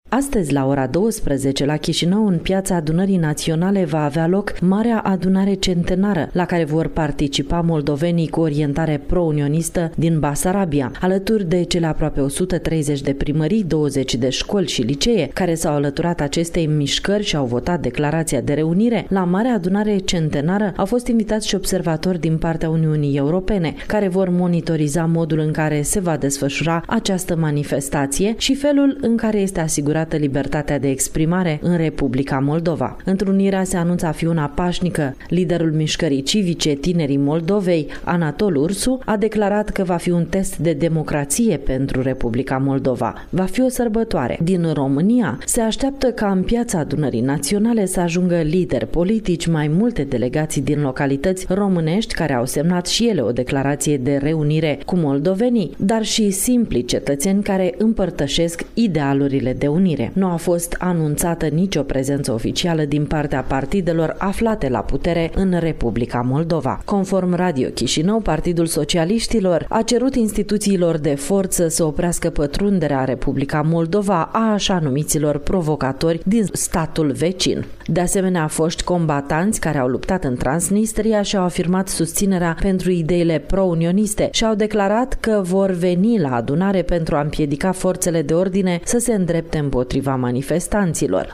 Transmite din Chişinău